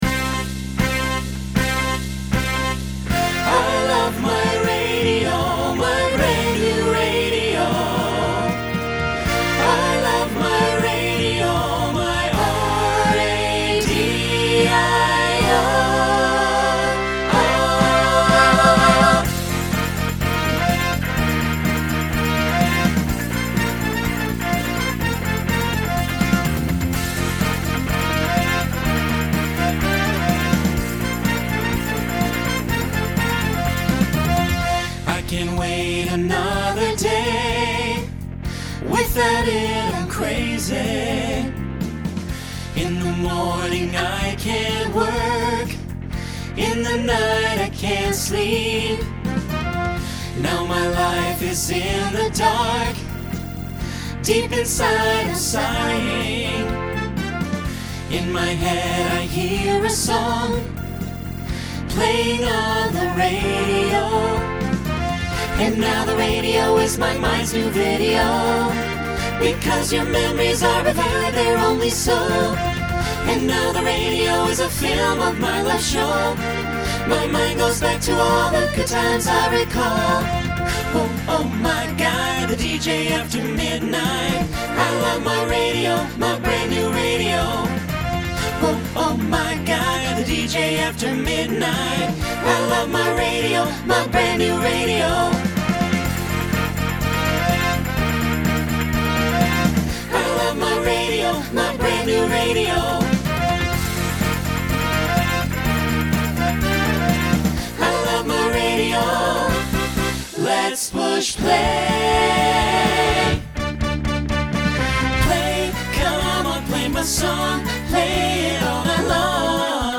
Genre Pop/Dance Instrumental combo
Voicing SAB